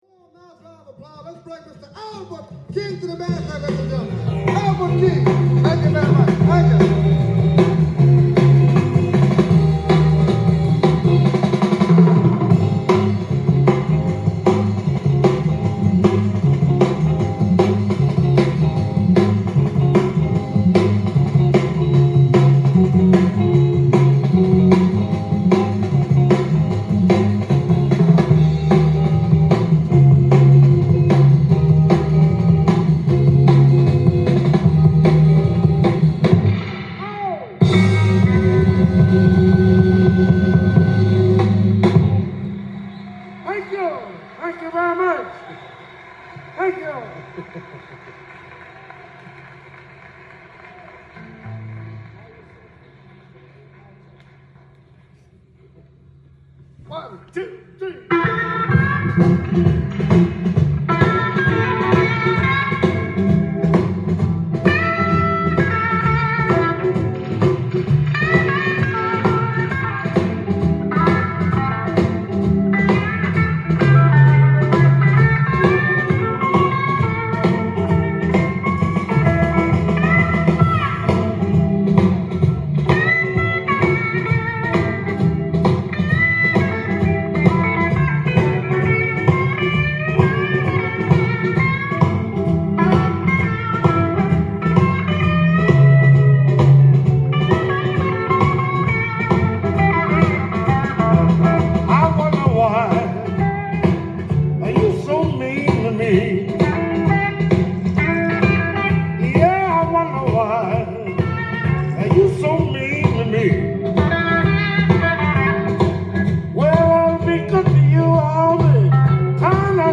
ジャンル：BLUES
店頭で録音した音源の為、多少の外部音や音質の悪さはございますが、サンプルとしてご視聴ください。